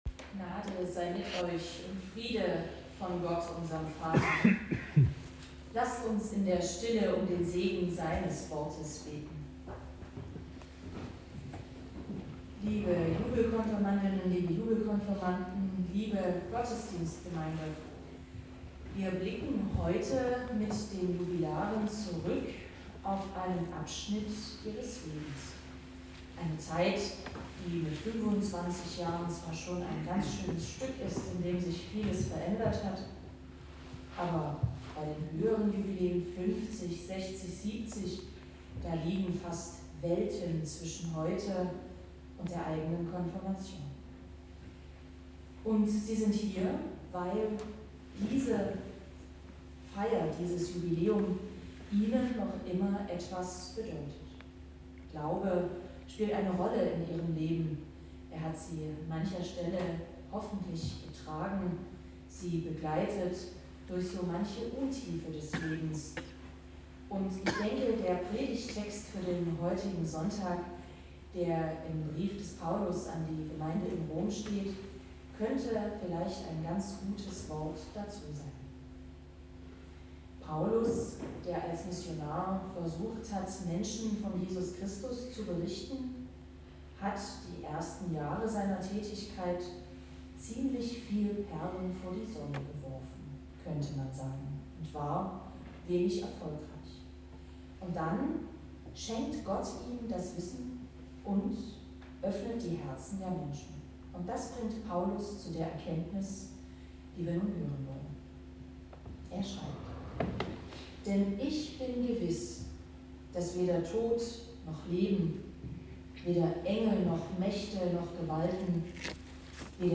Gottesdienstart: Jubelkonfirmation